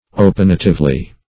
opinatively - definition of opinatively - synonyms, pronunciation, spelling from Free Dictionary
-- O*pin"a*tive*ly , adv.